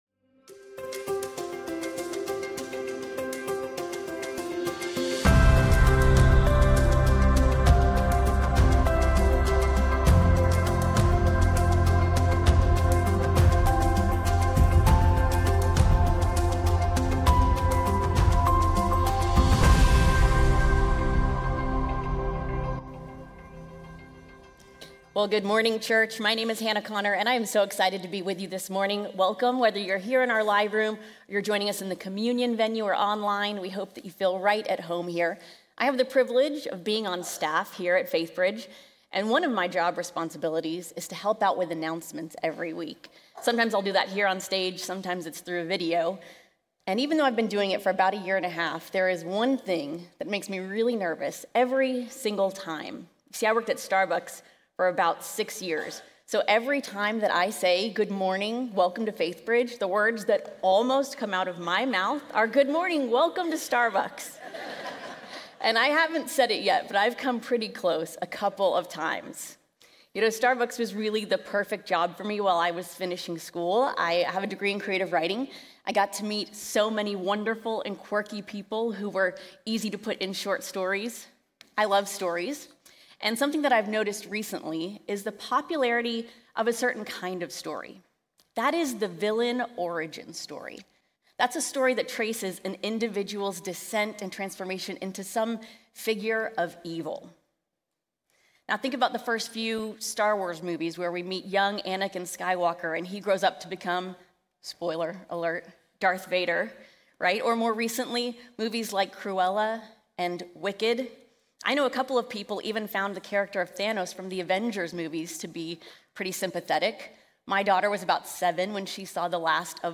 Faithbridge Sermons Saul's Disobedience Jul 27 2025 | 00:28:16 1x 00:00 / 00:28:16 Subscribe Share Apple Podcasts Spotify Overcast RSS Feed Share Link Embed